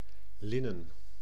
Ääntäminen
Synonyymit vêtement Ääntäminen France: IPA: /lɛ̃ʒ/ Haettu sana löytyi näillä lähdekielillä: ranska Käännös Ääninäyte Substantiivit 1. doek {m} 2. was {m} Muut/tuntemattomat 3. linnen {n} 4. wasgoed {n} Suku: m .